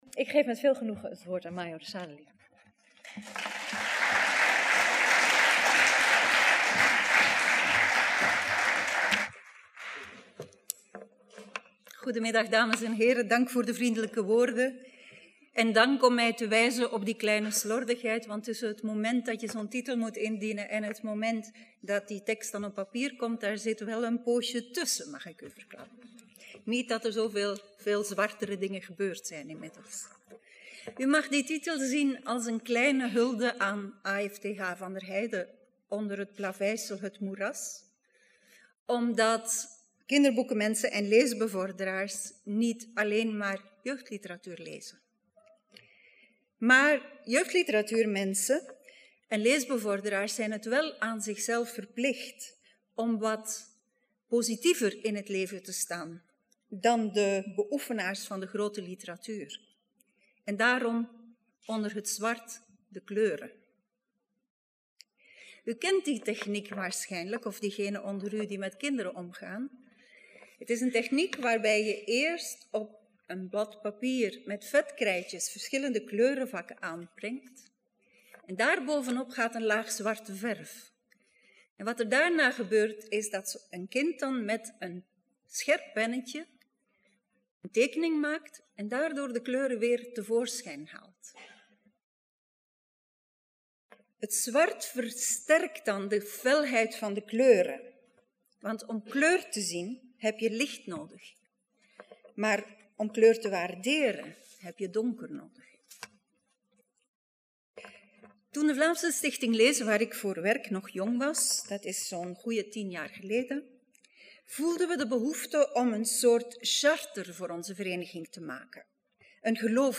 Woutertje Pieterse lezing 2013